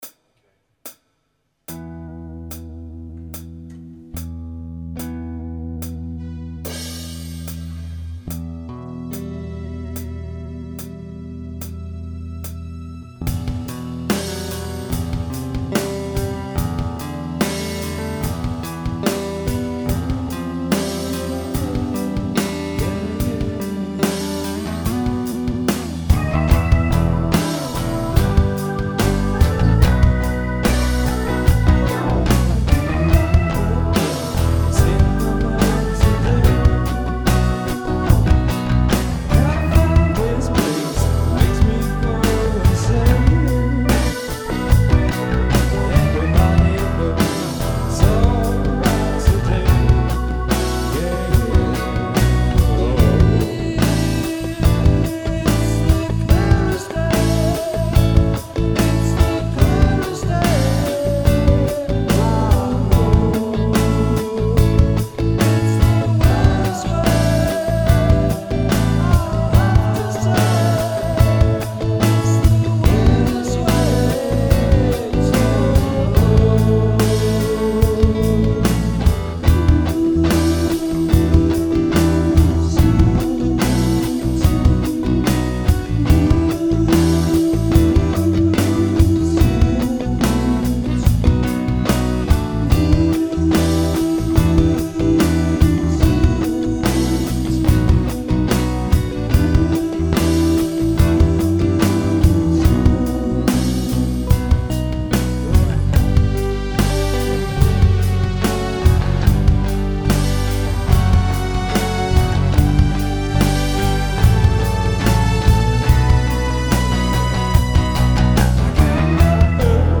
(live)
keyboards, bass and harmony vocals
guitar and lead vocals
flute, harmony vocals and lyrics